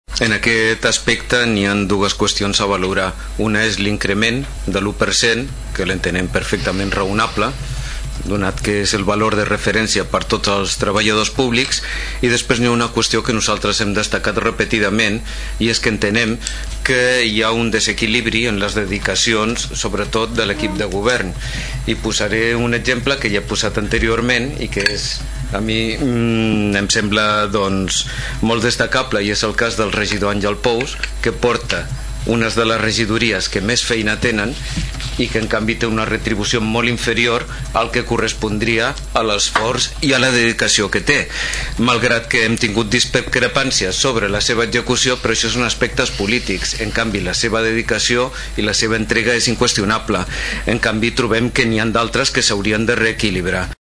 IMGP2097El passat Ple de l’Ajuntament de Tordera, va aprovar l’augment del sou de l’1% dels representants de la corporació local.
El grup socialista es va abstenir, el regidor Rafa Delgado plantejava com a positiu l’augment de sou, al contrari exposava el desequilibri en les retribucions entre els regidors.